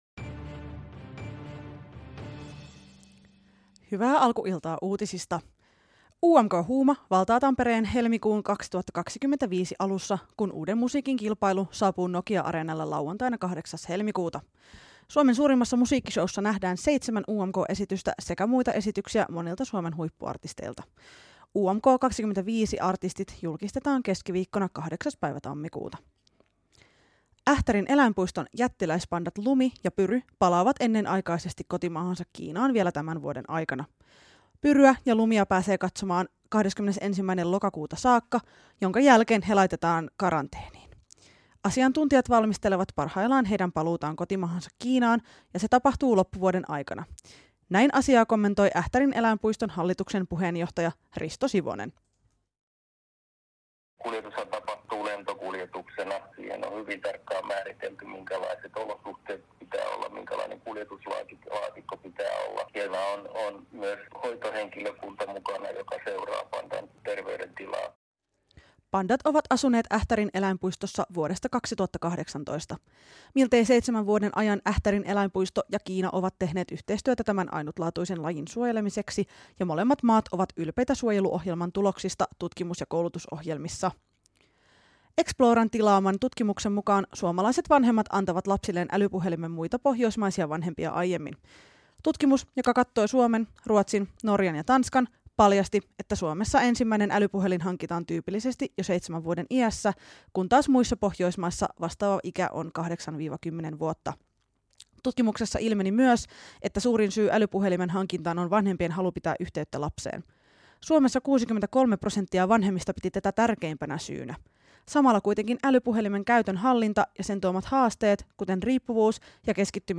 Kaikki tämä ja paljon muuta selviää Skidin myöhäisillan radiolähetyksessä!